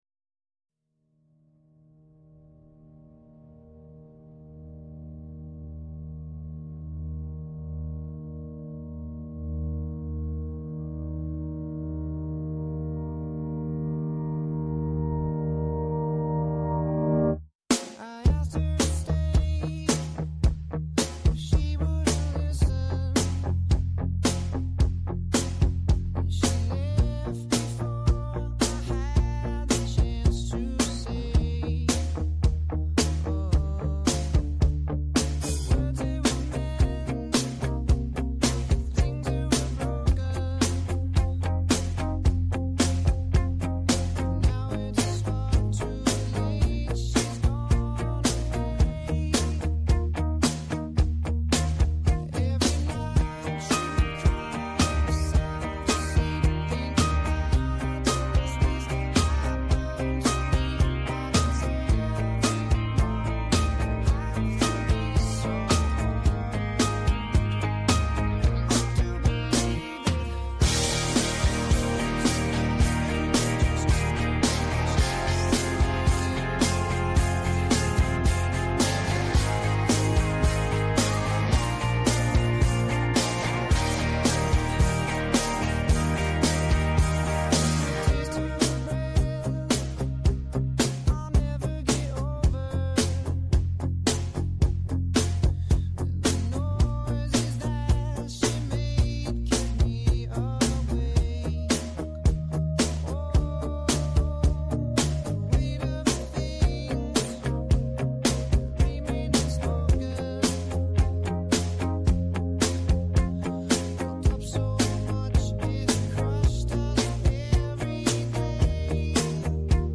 (Karaoke Version)